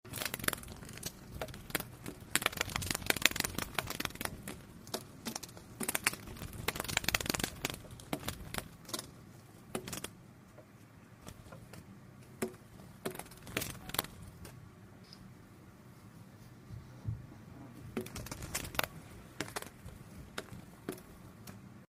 効果音 虫の羽音 (着信音無料)